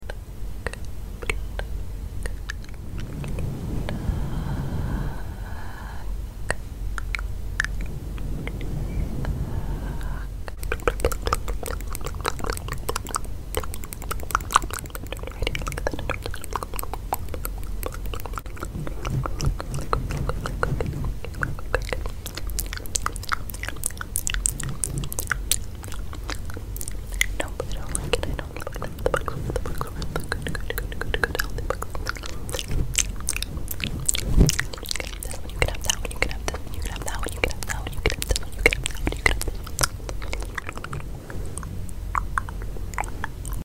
ASMR searching for bugs in sound effects free download
ASMR searching for bugs in your hair very slowly…then very quicly
⚠mouth sounds⚠